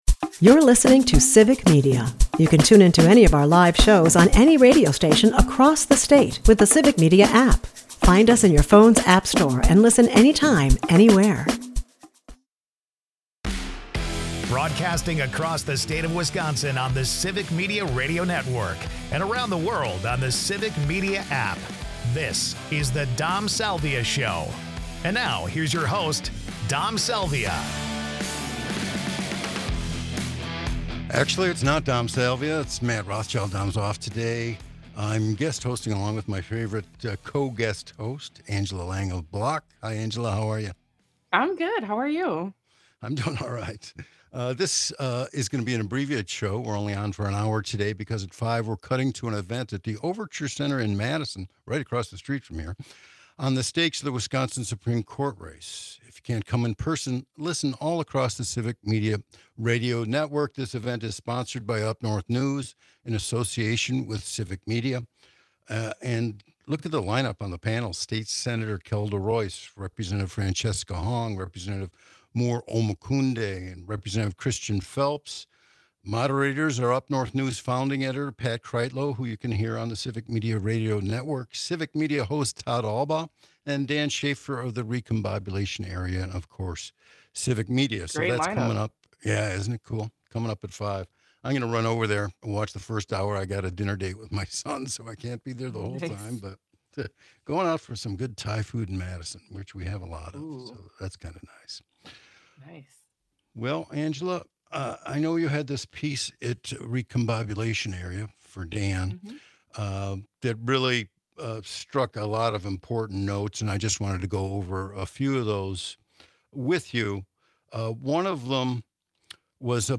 Enjoy Audre Lorde's "A Woman Speaks," some bird calls, and a call to come together in community.